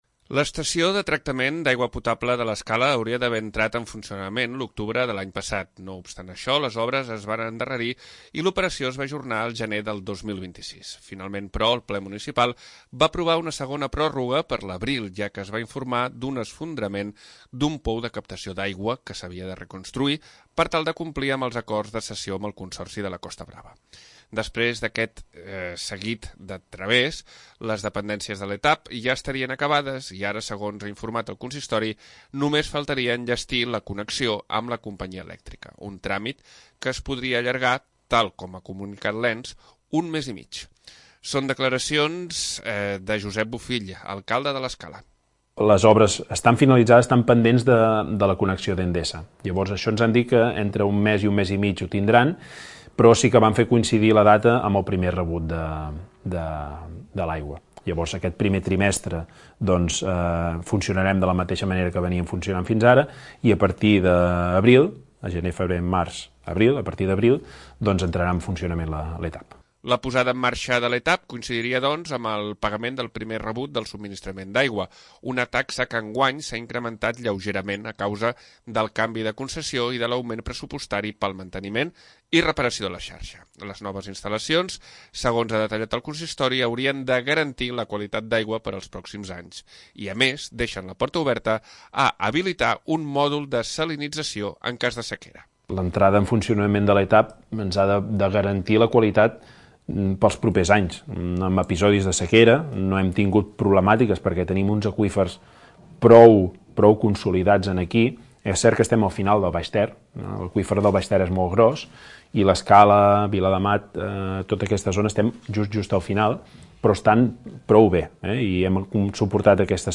Pel que fa a la resta d'equipaments, l'alcalde, Josep Bofill, ha explicat, en el programa 'Línia Directa', que el municipi està treballant per a tenir unes dependències municipals de "primer nivell".